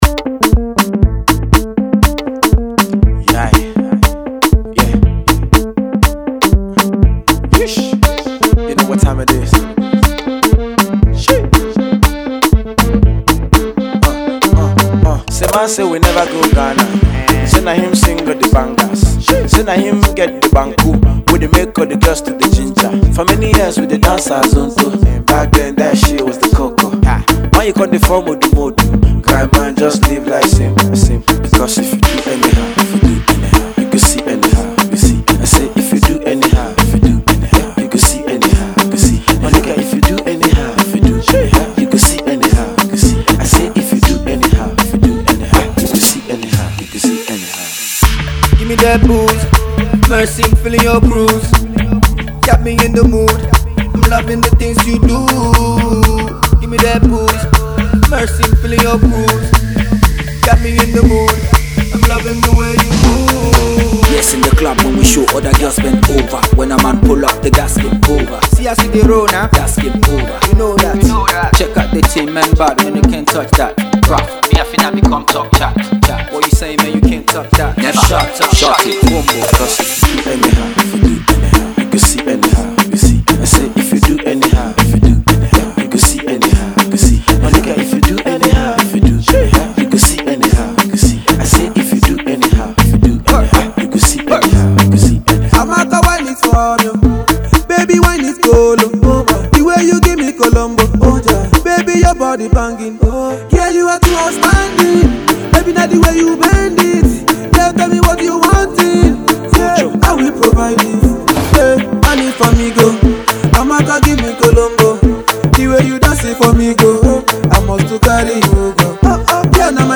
high-octane uptempo cut